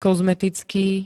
kozmetický [-t-] -ká -ké príd.
Zvukové nahrávky niektorých slov